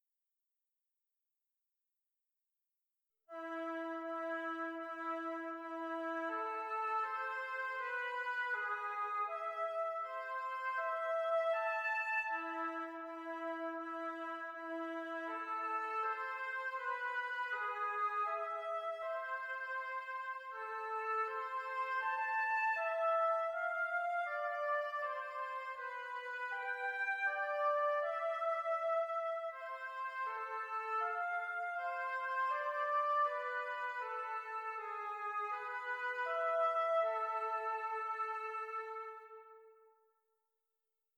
A few days ago I was sent this beautful melancholic melody sketch by a composer and I was asked whether I could arrange it to an orchestral piece?
- a melody sketch from 1820 :-)